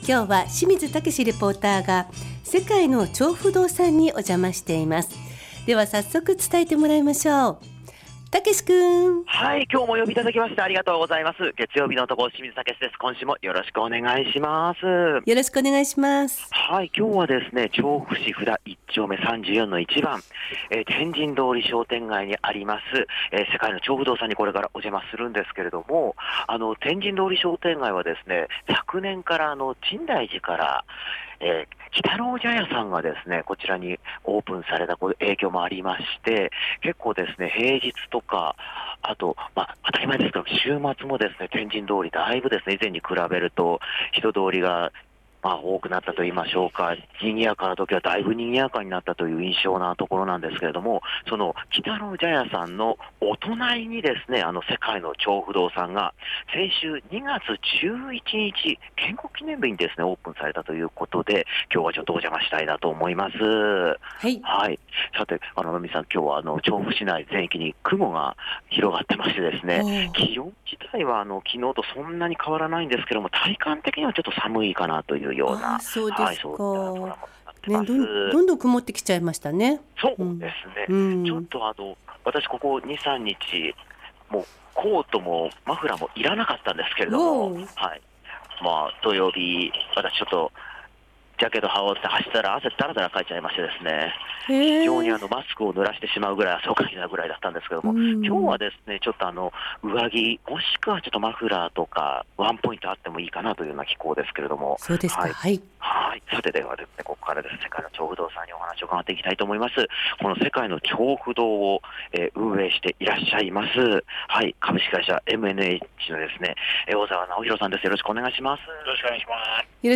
徐々に雲行きが怪しい空の下からお届けした本日の街角レポートは、2月11日オープンしたばかりの「世界の調布堂」さんにお伺い致しました！